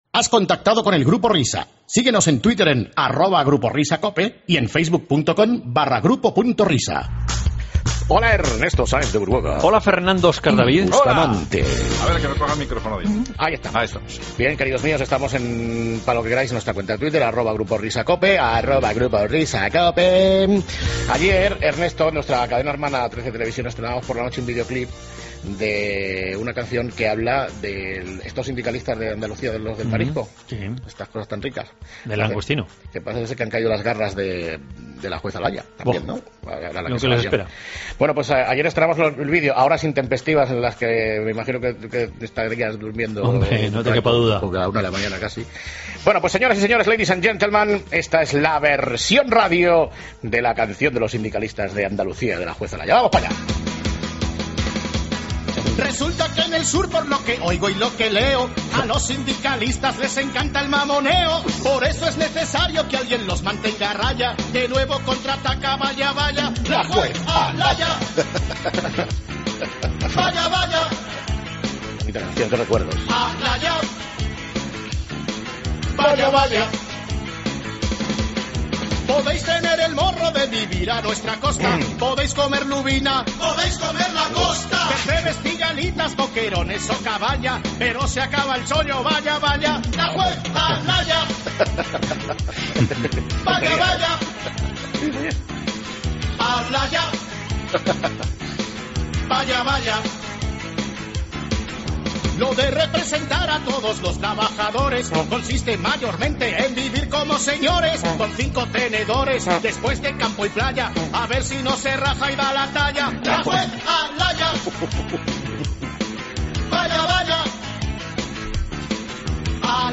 AUDIO: Canción a la Juez Alaya y Montoro después de su paso por La Linterna